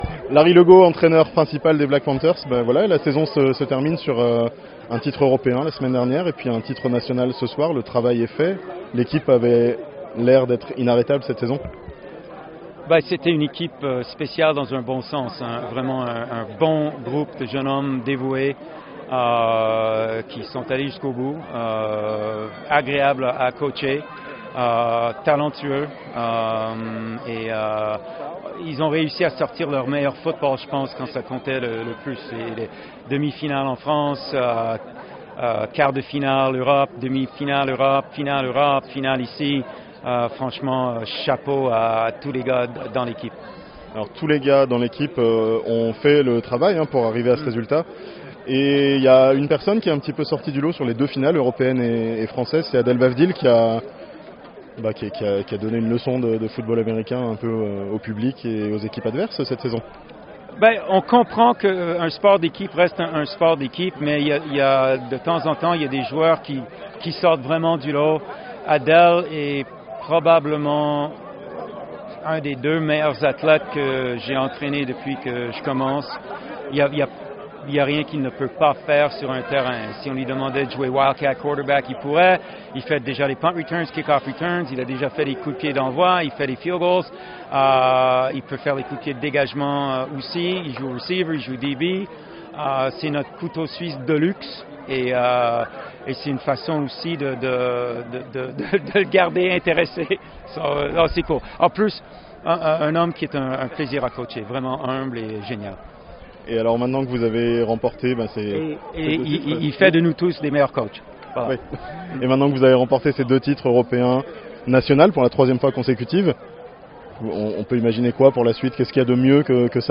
Les Black Panthers s'offrent le doublé France / Europe (interview)